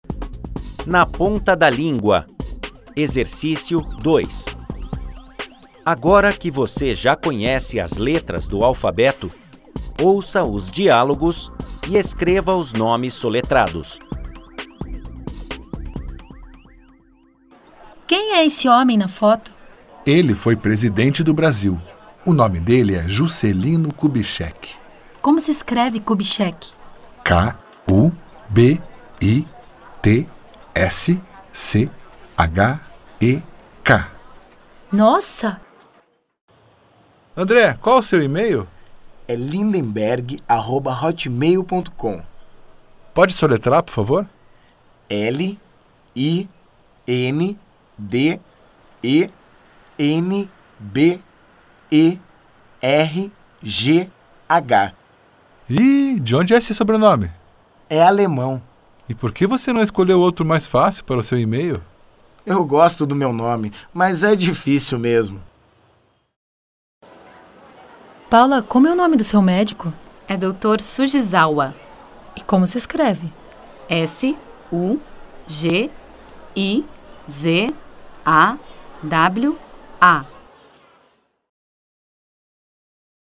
Faixa 2 Nomes soletrados.mp3